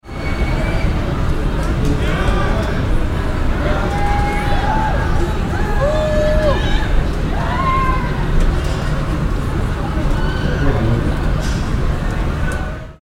Live-concert-audience-noise.mp3